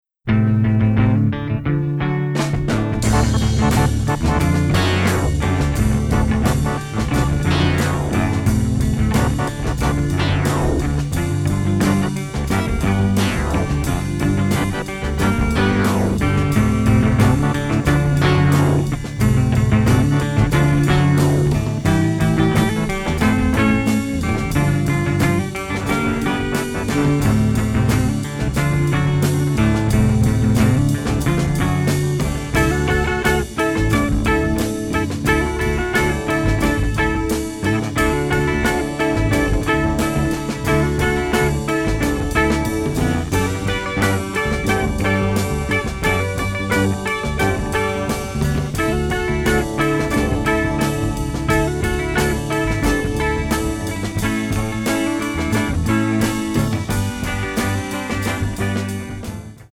Italian masterpiece of psychedelia!